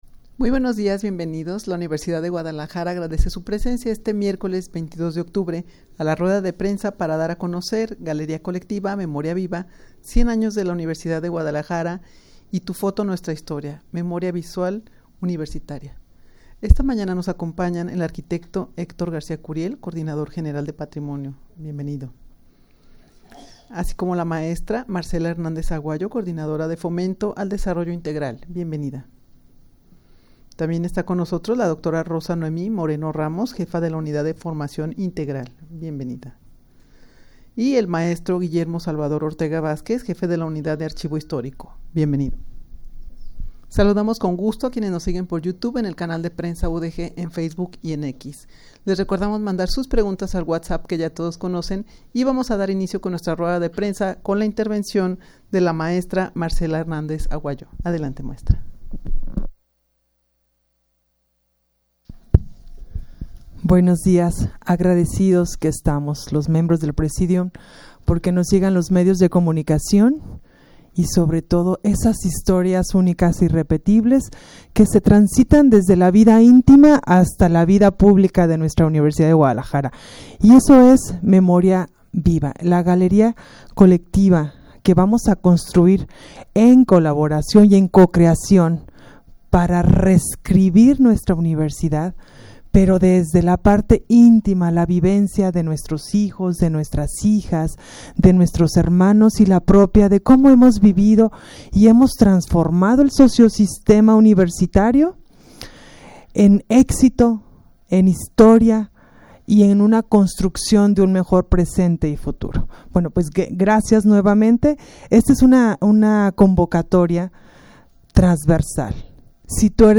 Audio de la Rueda de Prensa
rueda-de-prensa-para-dar-a-conocer-galeria-colectiva-memoria-viva.-100-anos-de-la-universidad-de-guadalajara.mp3